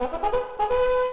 Bugle
BUGLE.wav